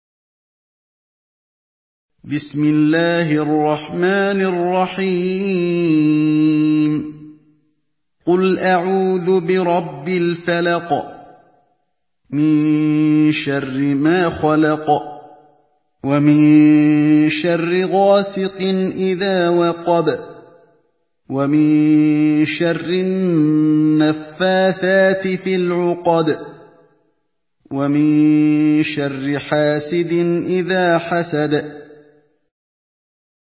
Surah الفلق MP3 by علي الحذيفي in حفص عن عاصم narration.
مرتل حفص عن عاصم